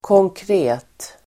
Uttal: [kångkr'e:t]